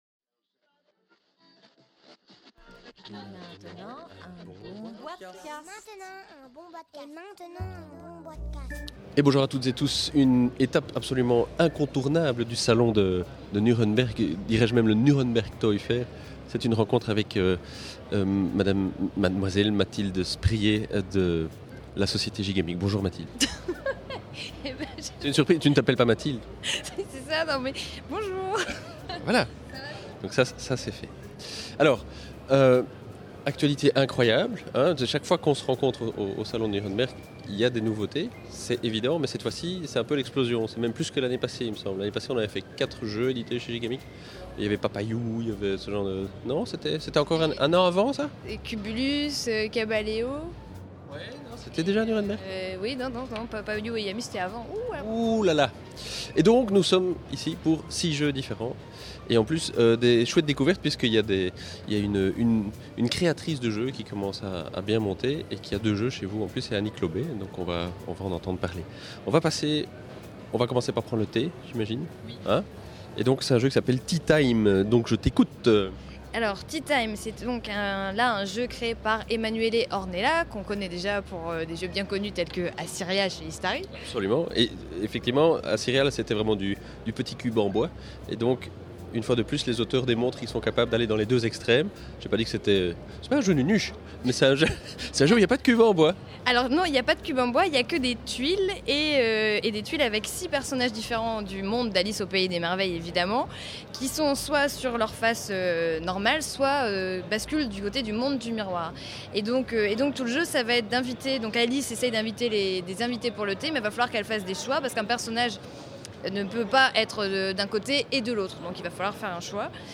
(enregistré en février 2012 lors de la Nuremberg Toy Fair)